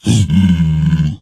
zpigangry1.ogg